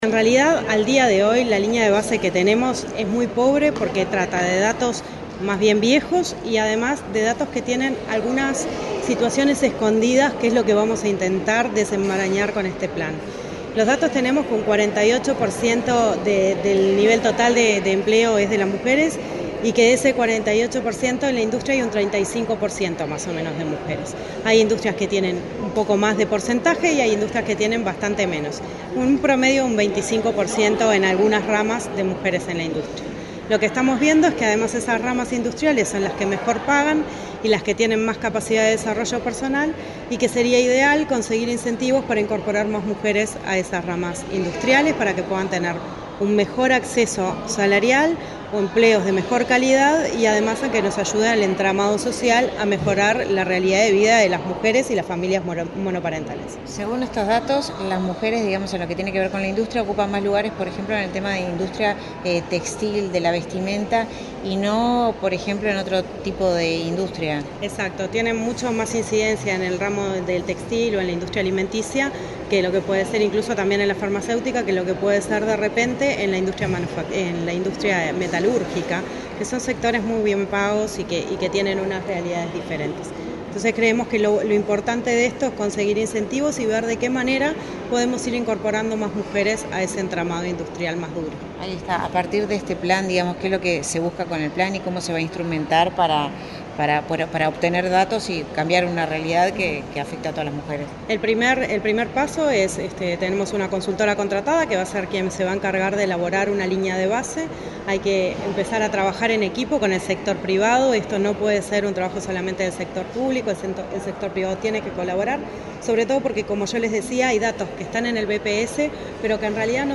Declaraciones de la directora nacional de Industrias, Susana Pecoy
Este jueves 27 en la Torre Ejecutiva, la directora nacional de Industrias, Susana Pecoy, dialogó con la prensa, luego de participar en la presentación